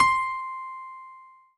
PIANO5-11.wav